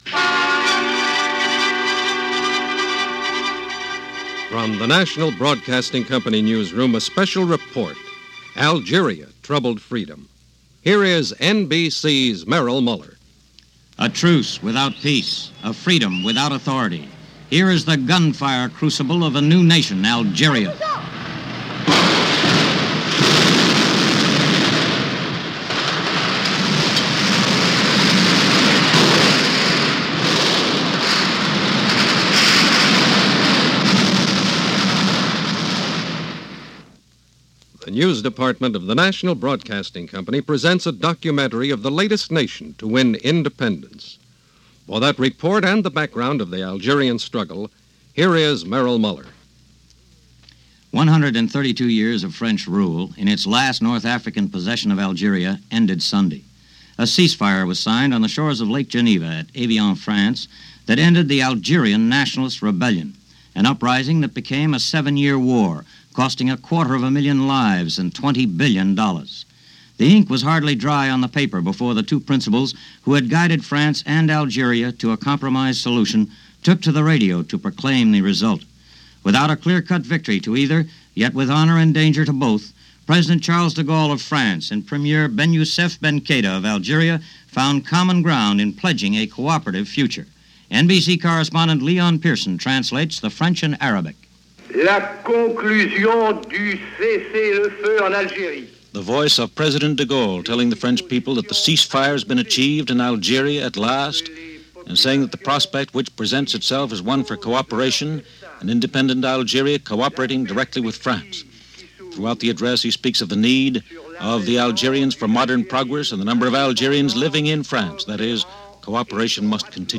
March 1962 - Algeria independence - A radio news documentary on the signing of the ceasefire and the independence of the former French colony of Algeria.